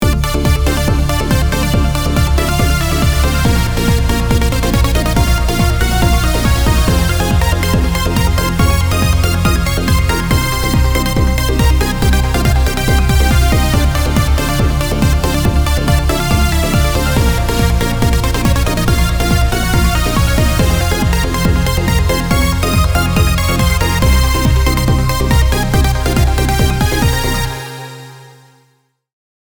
RPGの戦闘曲のイントロのようなイメージです。
こちら、Sylenth1 のファクトリープリセットだけで作りました。
冒頭の音源でもこのプリセットを使用しています。